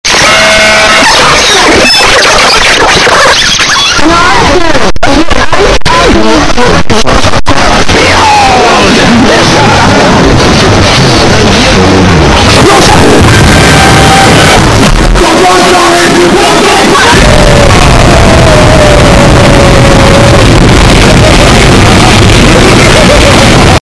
(WARNING: LOUD/EARRAPE)